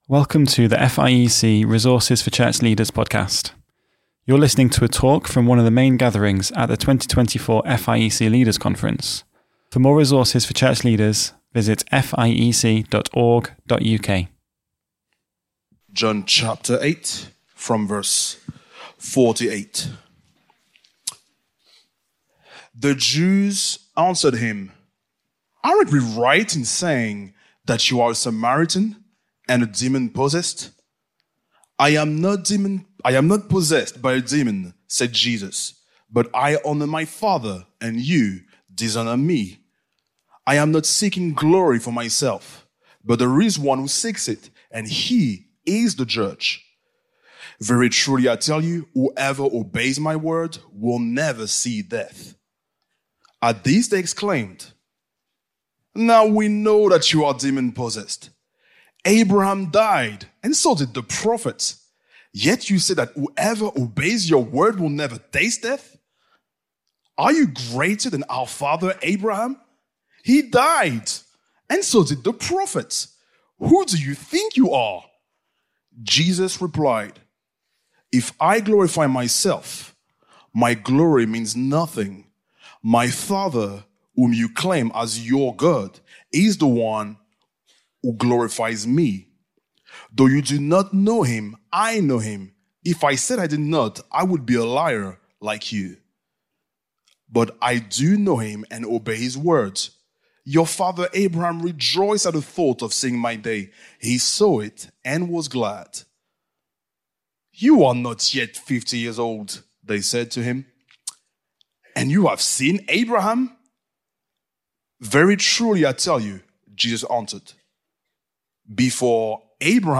Before-Abraham-Was-I-AM-FIEC-Leaders-Conference-2024.mp3